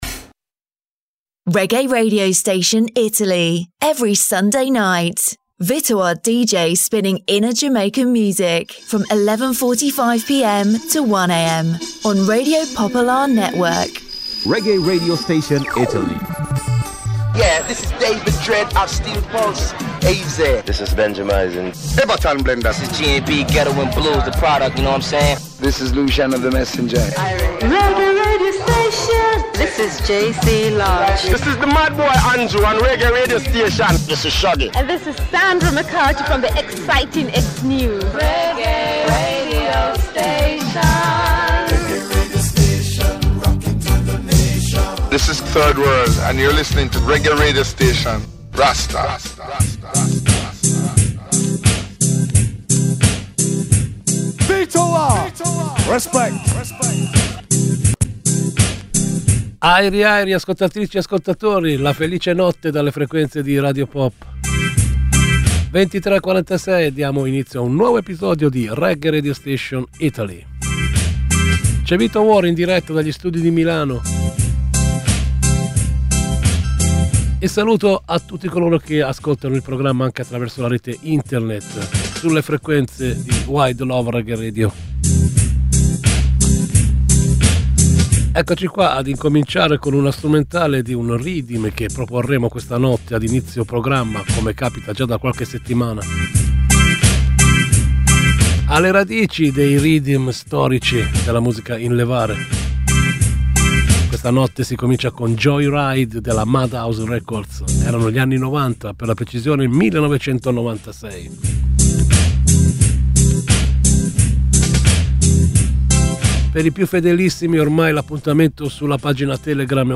A ritmo di Reggae Reggae Radio Station accompagna discretamente l’ascoltatore in un viaggio attraverso le svariate sonorità della Reggae Music e...